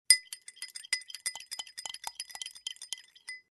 Звуки ложки
Звук перемешивания чая ложкой в граненом стакане